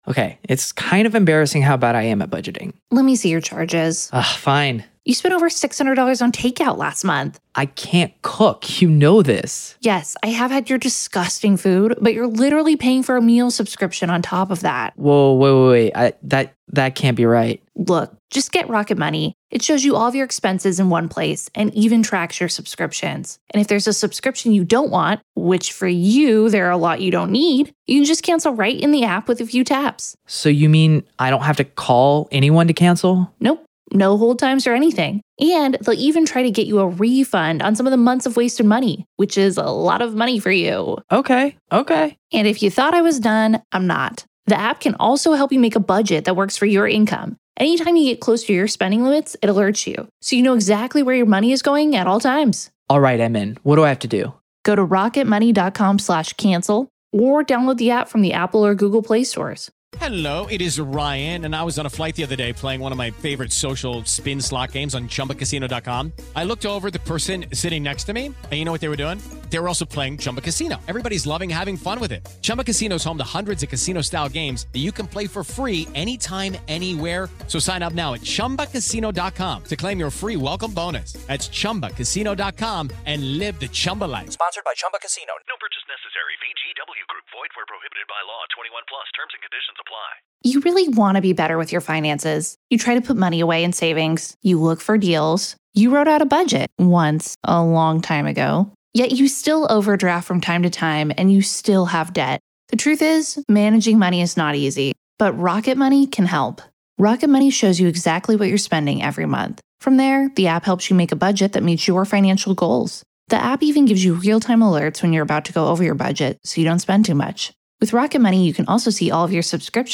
joins hands with a rotating roster of guests, sharing their insights and analysis on a collection of intriguing, perplexing, and often chilling stories ...